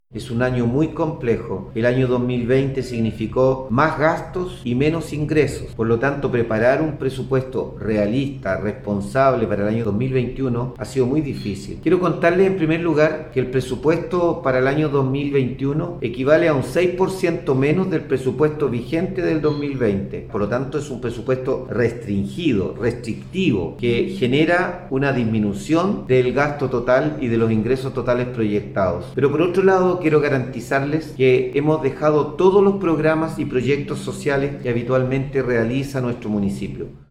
01-ALCALDE-MELLA-Presupuesto-restrictivo.mp3